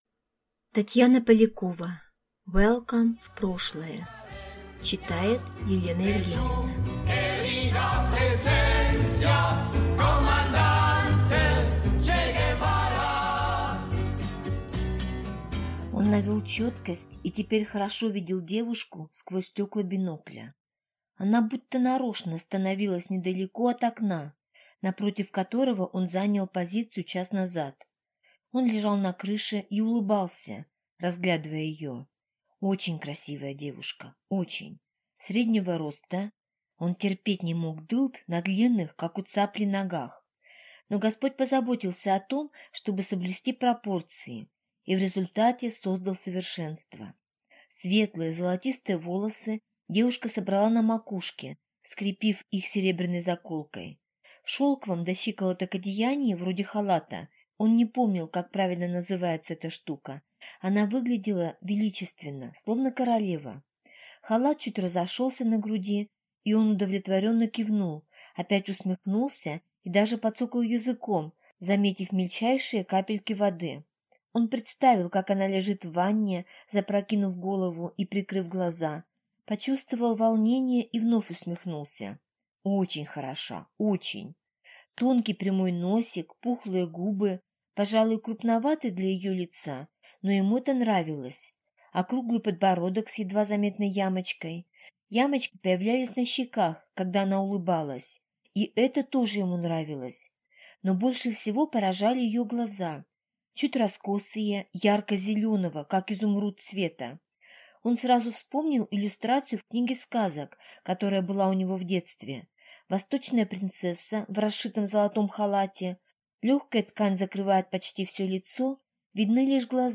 Аудиокнига Welcome в прошлое | Библиотека аудиокниг